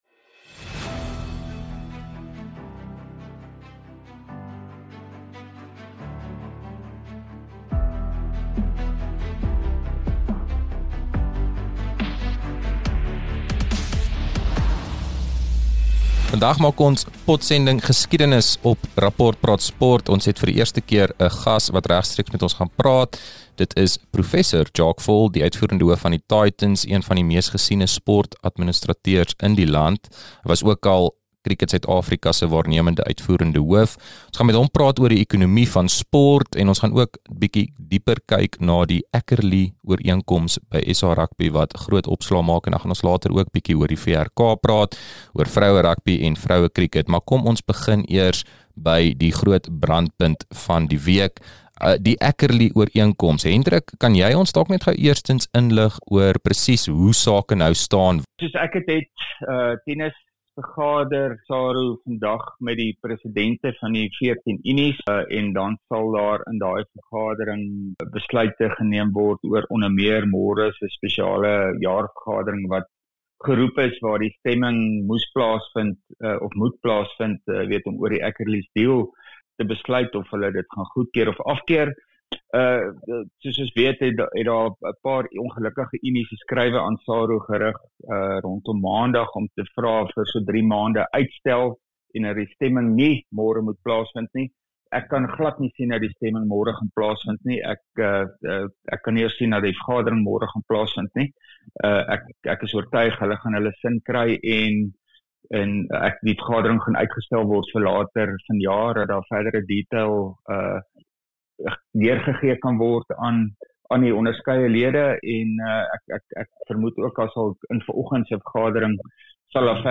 Ons praat ook oor Gayton McKenzie, die minster van sport, kuns en kultuur, se betrokkenheid by die voornemende ooreenkoms. Dit is ook die eerste keer dat ’n gas hom regstreeks by ons aansluit.